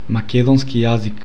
Pronunciation[maˈkɛdɔnski ˈjazik]